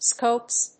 /skops(米国英語), skəʊps(英国英語)/